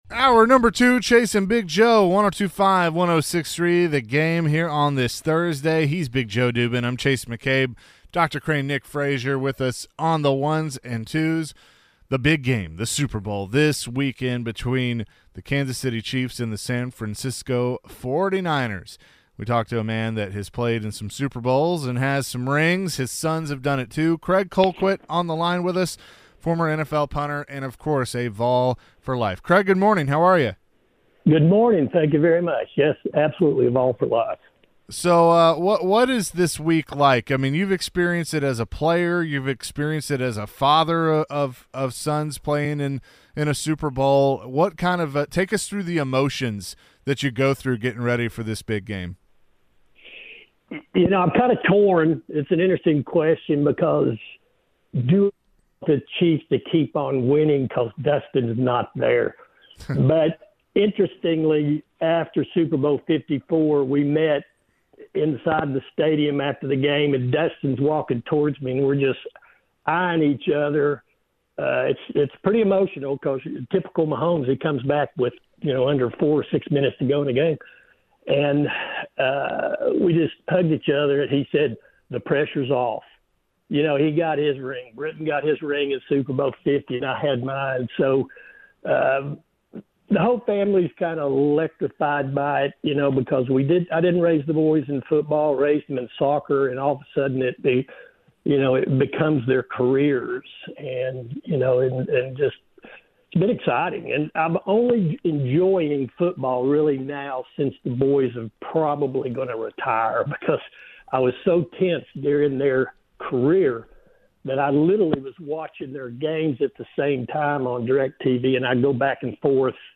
Former NFL punter and Super Bowl champion Craig Colquitt joins the show in the second hour.